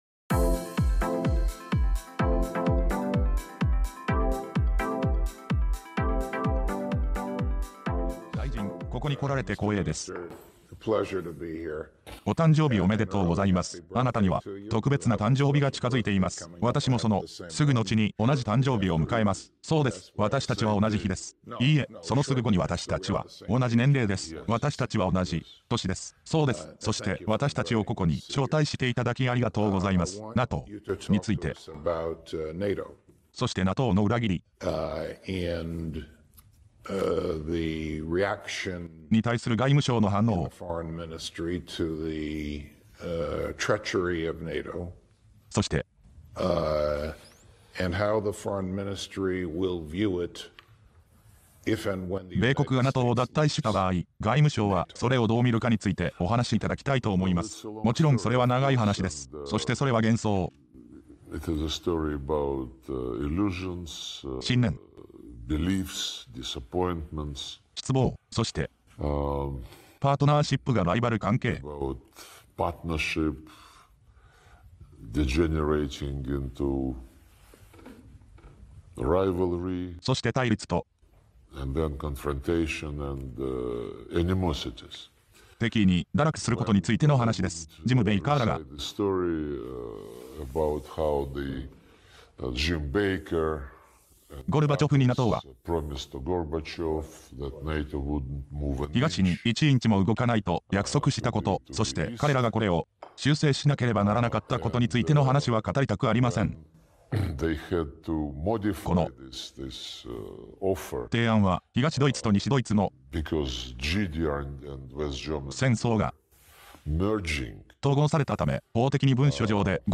2025年3月12日、モスクワで行われた米国のブロガー、マリオ・ナウファル、ラリー・C・ジョンソン、アンドリュー・ナポリターノとのインタビューの中で、ロシアのセルゲイ・ラブロフ外相は、ウクライナ紛争におけるNATOの役割について自身の見解を語った。